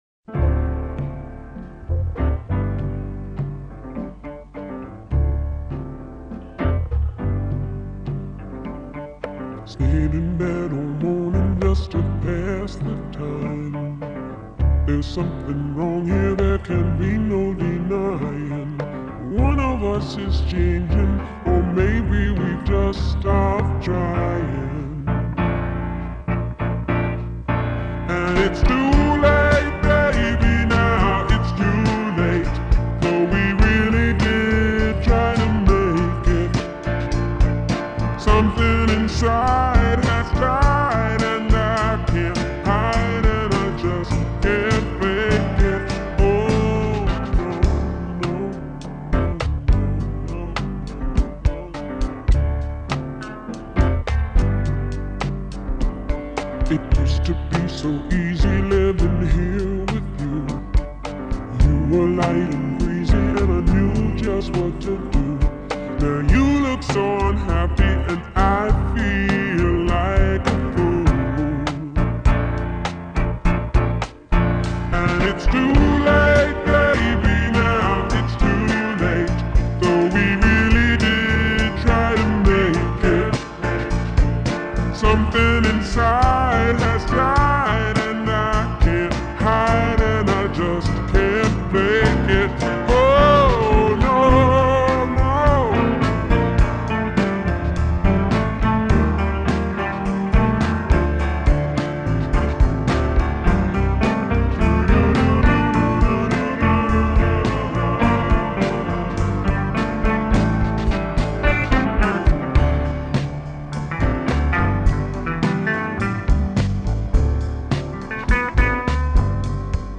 (Em)